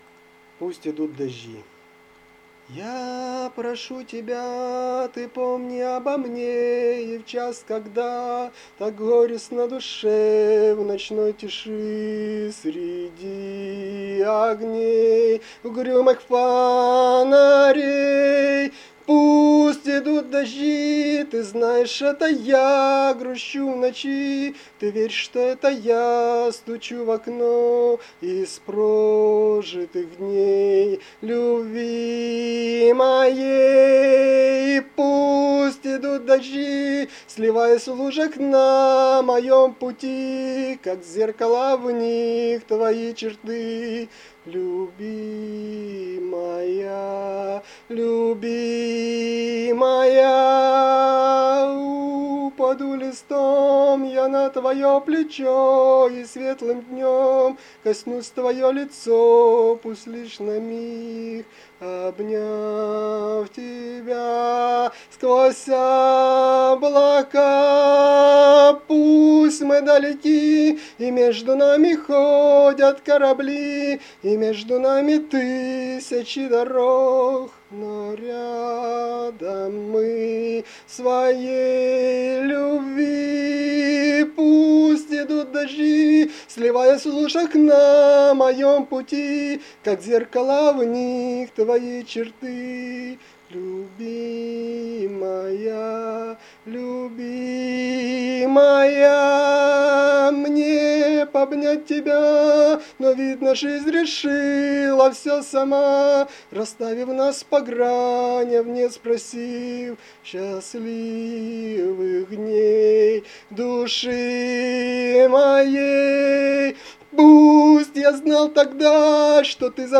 Вариация на музыку песни
Рубрика: Поезія, Авторська пісня